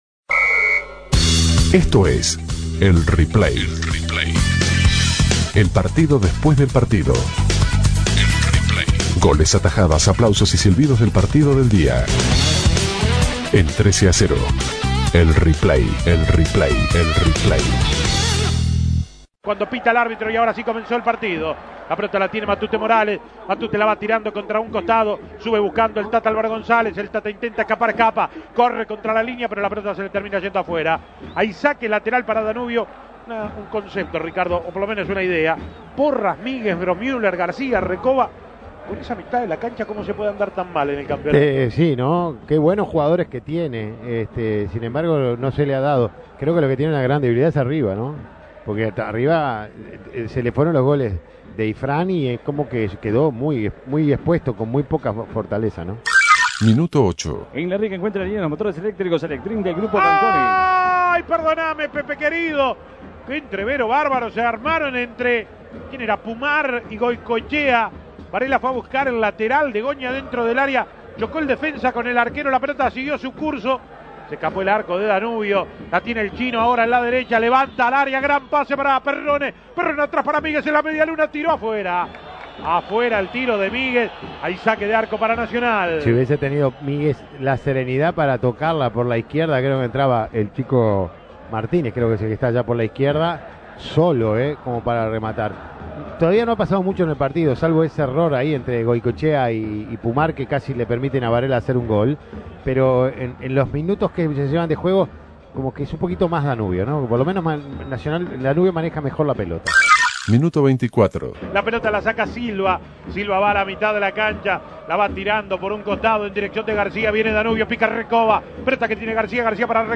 Escuche los goles y las principales incidencias del partido entre nacional y Danubio.
Goles y comentarios El Replay del partido Nacional - Danubio Imprimir A- A A+ Escuche los goles y las principales incidencias del partido entre nacional y Danubio.